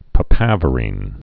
(pə-păvə-rēn, -ər-ĭn)